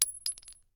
pistol_generic_3.ogg